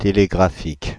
Ääntäminen
Synonyymit laconique Ääntäminen Paris: IPA: [te.le.ɡʁa.fik] France (Paris): IPA: /te.le.ɡʁa.fik/ Haettu sana löytyi näillä lähdekielillä: ranska Käännöksiä ei löytynyt valitulle kohdekielelle.